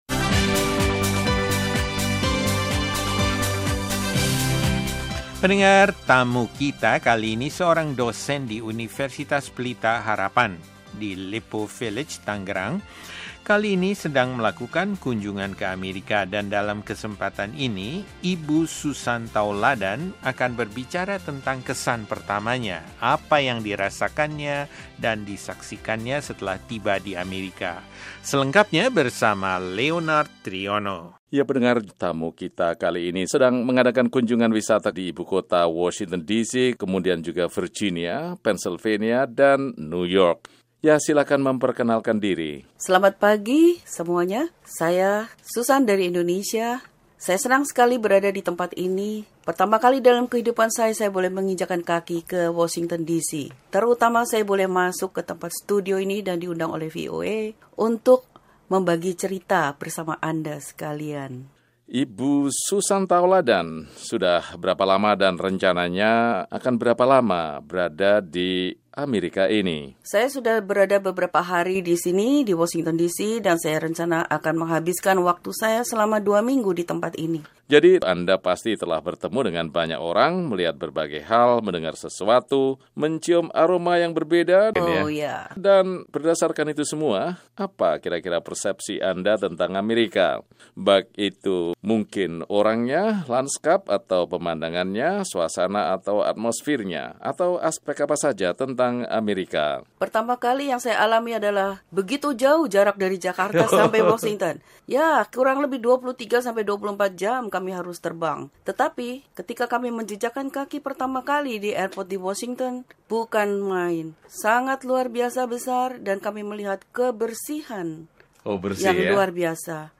Percakapan Ringan